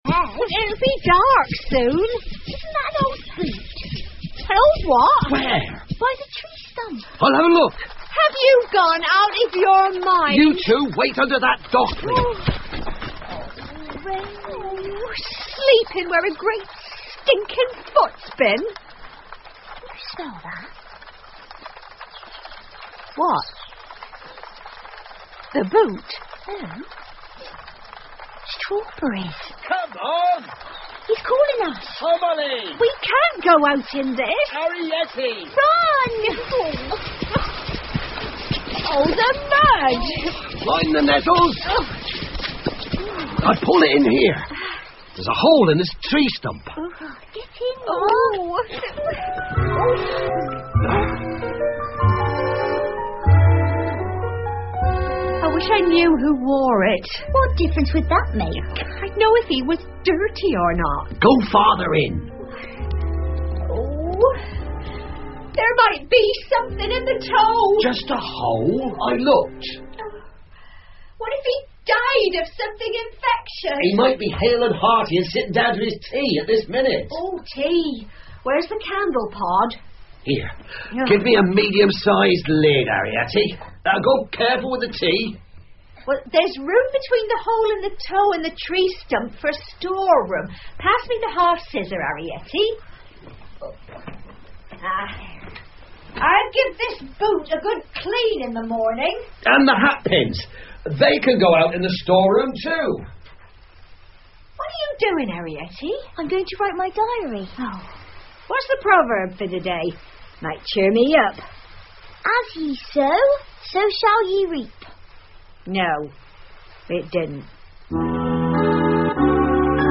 借东西的小人 The Borrowers 儿童广播剧 11 听力文件下载—在线英语听力室